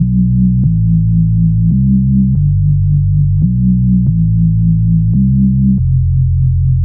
Index of /90_sSampleCDs/Club_Techno/Bass Loops
BASS_140_2-B.wav